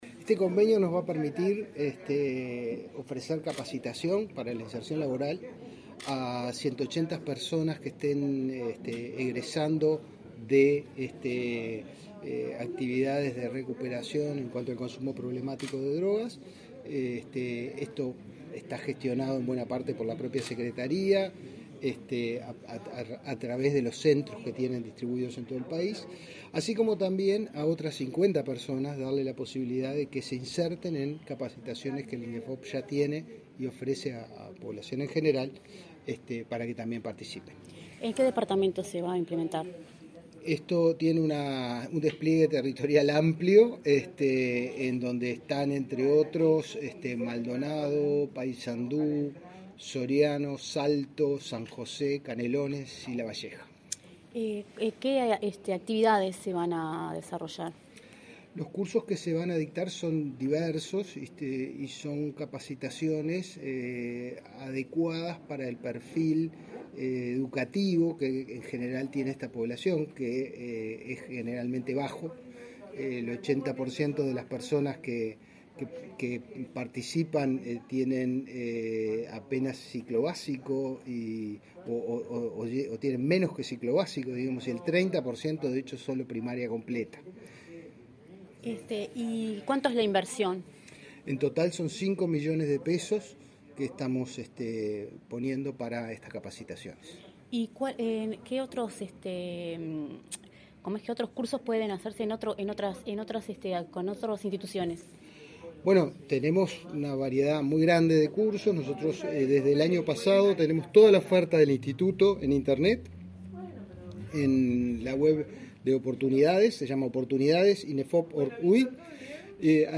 Entrevista al director de Inefop, Pablo Darscht